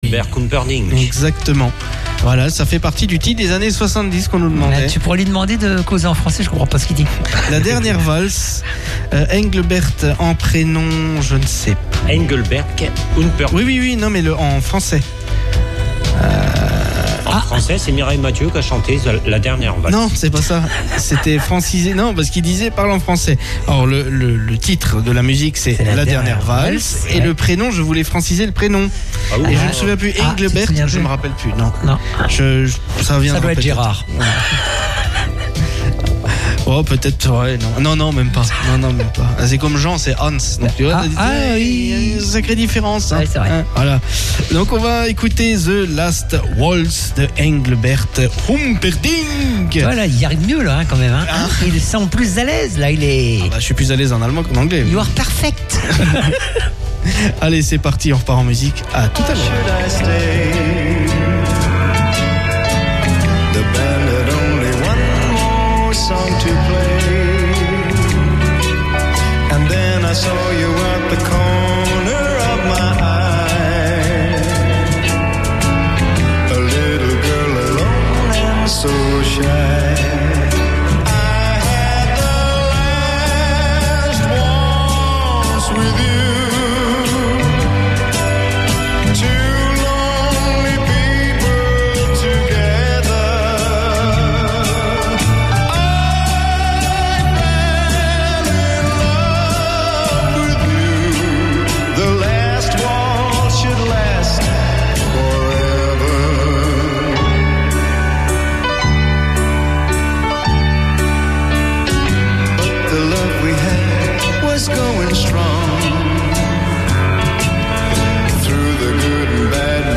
Aujourd’hui, votre émission Antenne Dimanche passe en mode 100% ROCK !